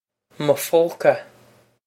mo phóca muh foe-ka
Pronunciation for how to say
This is an approximate phonetic pronunciation of the phrase.